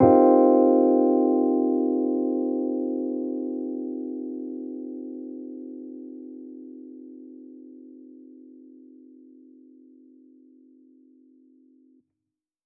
Index of /musicradar/jazz-keys-samples/Chord Hits/Electric Piano 2
JK_ElPiano2_Chord-Cm11.wav